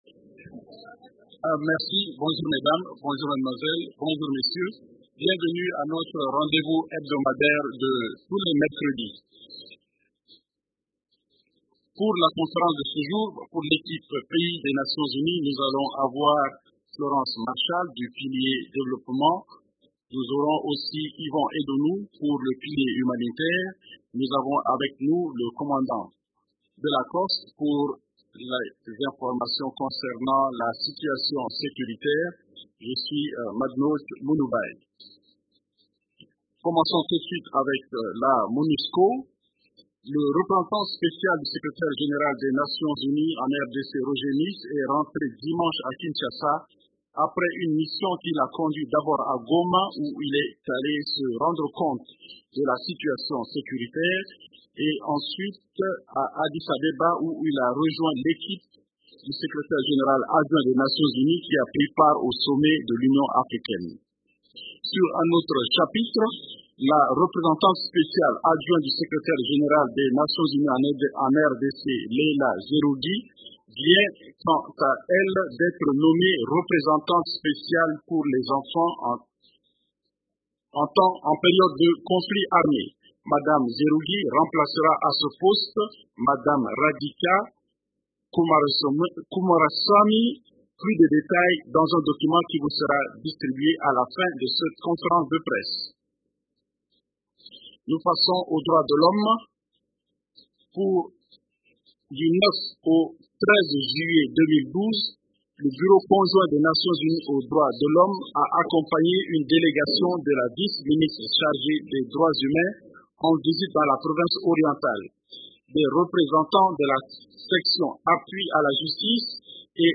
Conférence du 18 juillet 2012